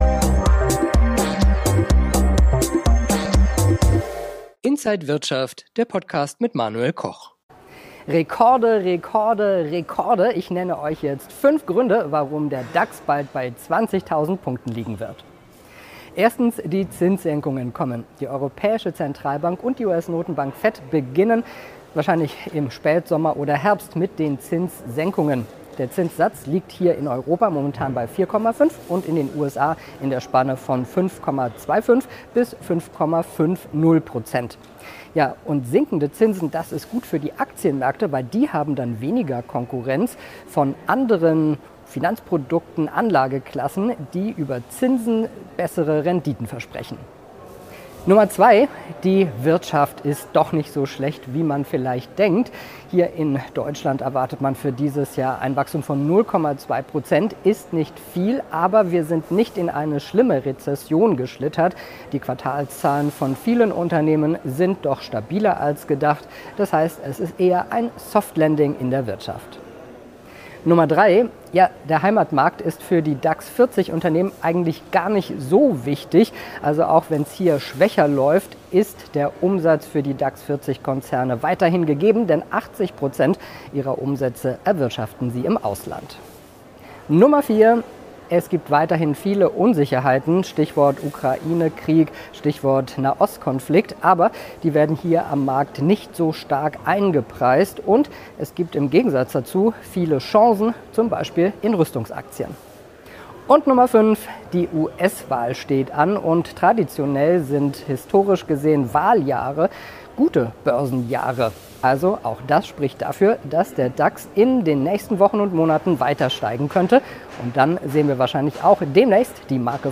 Alle Details im Interview